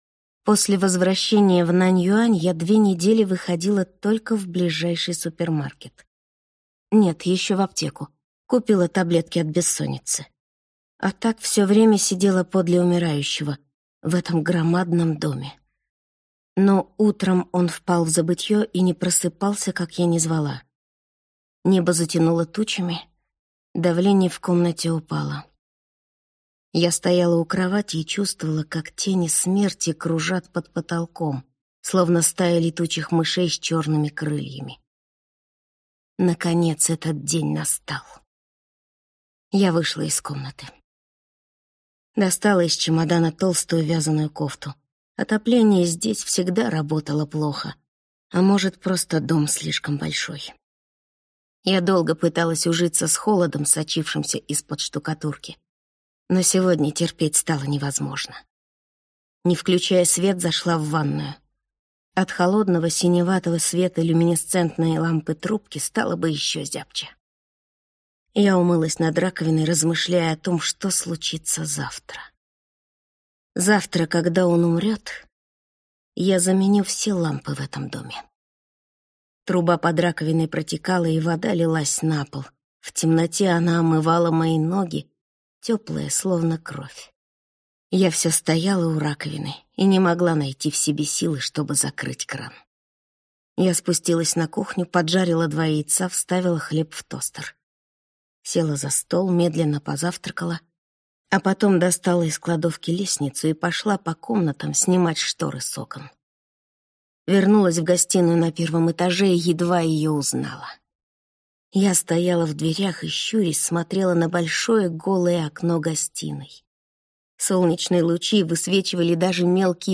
Аудиокнига Кокон | Библиотека аудиокниг